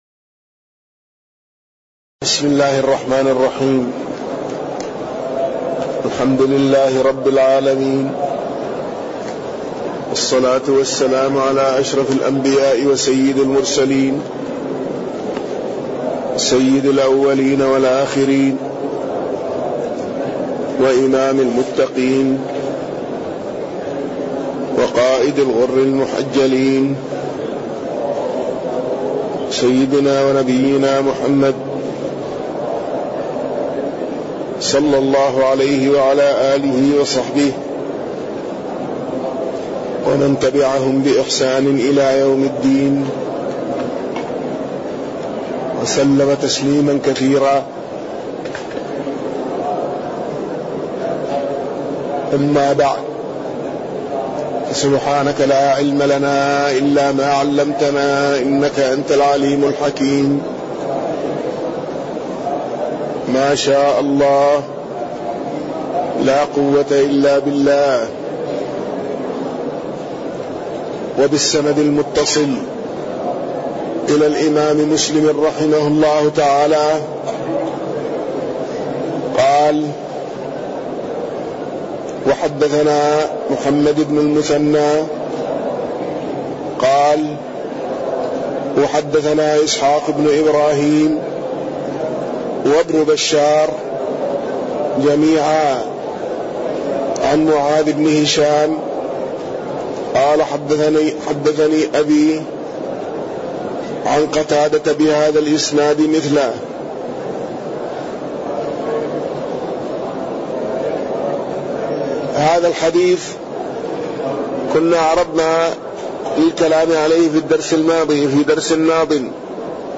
تاريخ النشر ١٨ شعبان ١٤٣٠ هـ المكان: المسجد النبوي الشيخ